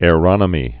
(â-rŏnə-mē)